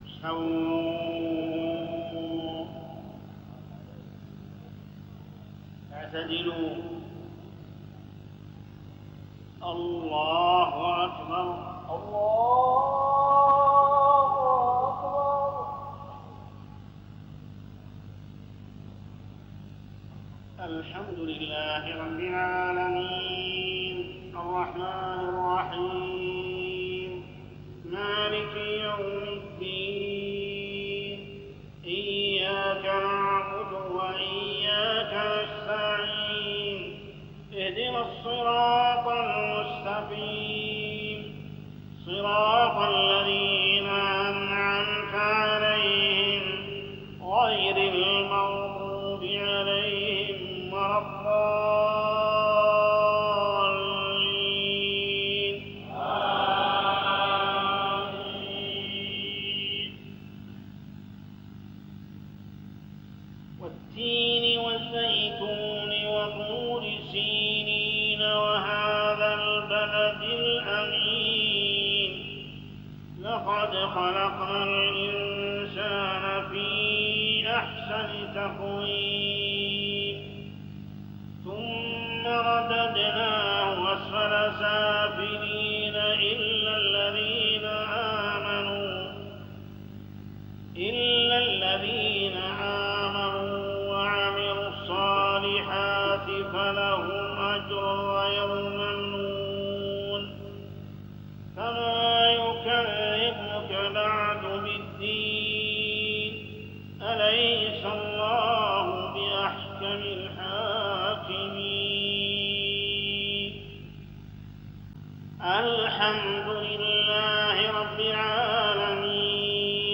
صلاة العشاء ليلة 22 رمضان 1419هـ سورتي التين و القدر > 1419 🕋 > الفروض - تلاوات الحرمين